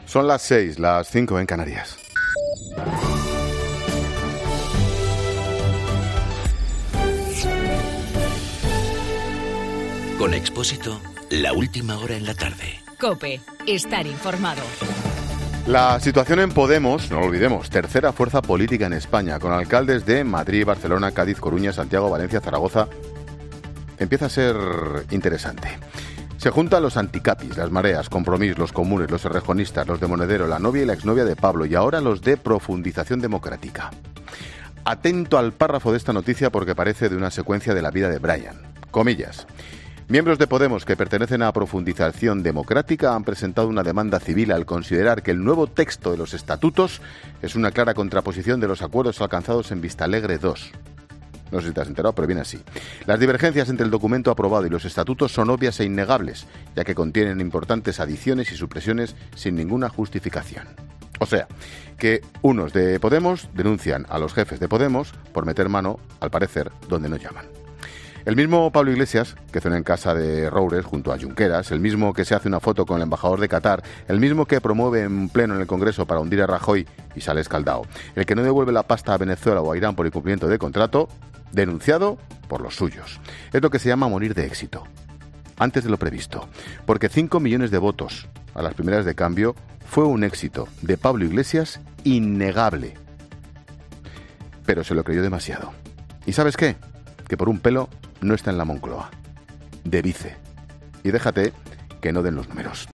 AUDIO: Ángel Expósito analiza en su monólogo de las 18h analiza la crisis interna del partido dirigido por Pablo Iglesias.